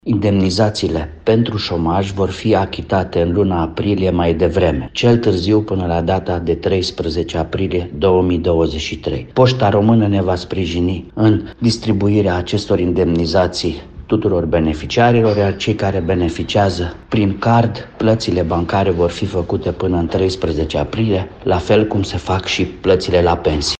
Agenţia Naţională pentru Ocuparea Forţei de Muncă anunţă devansarea plăților cuvenite beneficiarilor, spune secretarul general al instituției, Marcel Miclău.